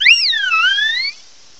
sovereignx/sound/direct_sound_samples/cries/sylveon.aif at master